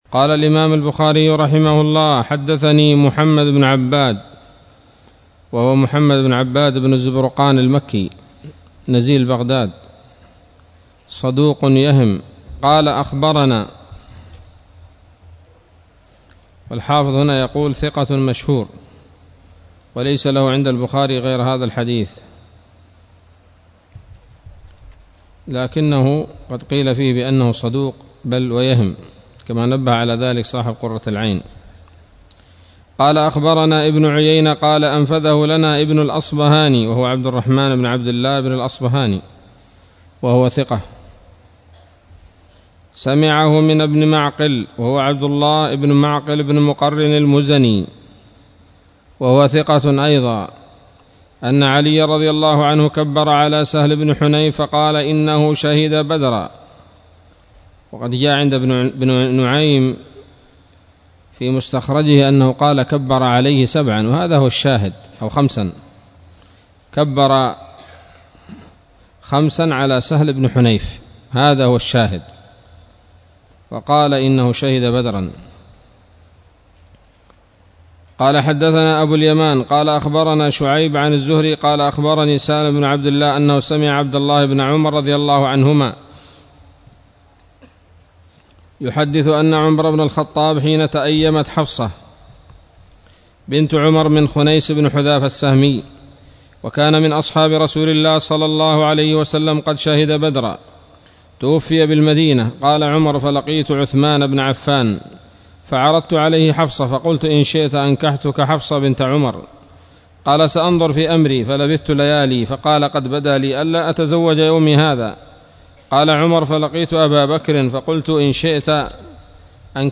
الدرس العشرون من كتاب المغازي من صحيح الإمام البخاري